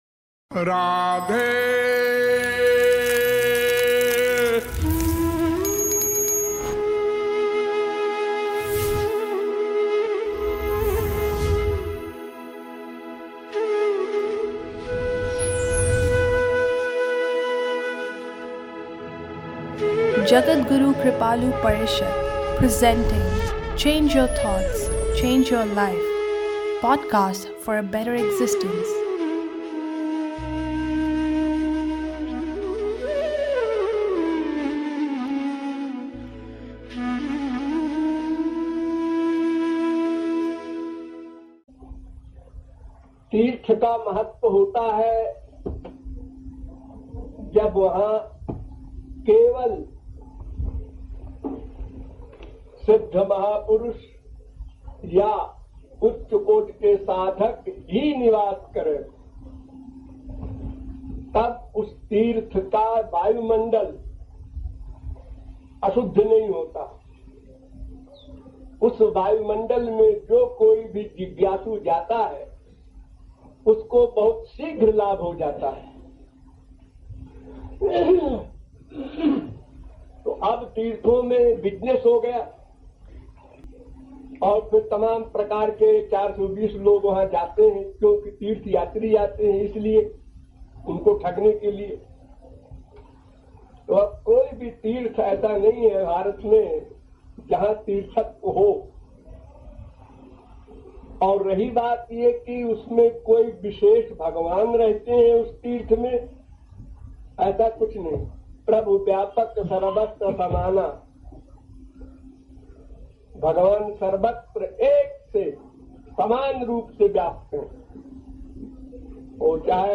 lecture on the importance of pilgrimage